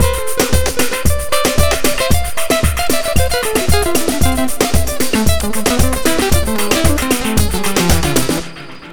Ala Brzl 2 Full Mix 4b-A.wav